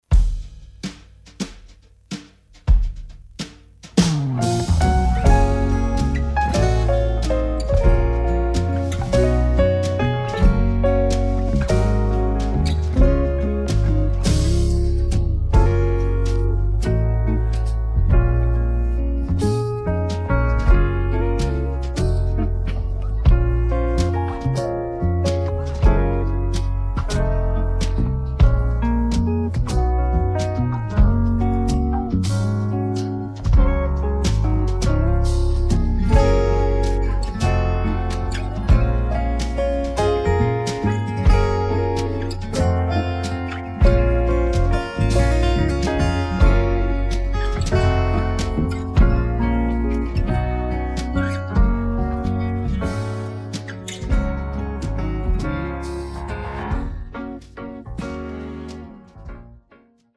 장르 가요 구분 Voice Cut